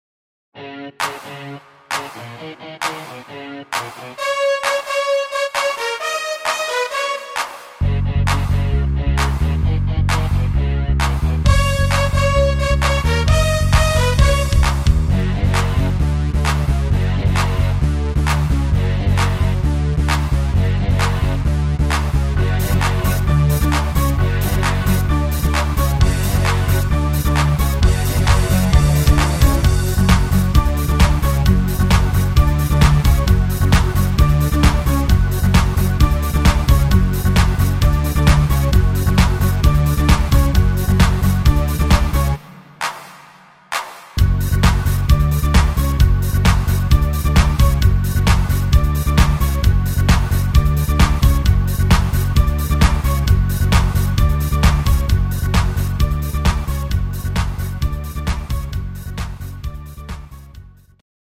Rhythmus  Dancefloor
Art  Pop, Englisch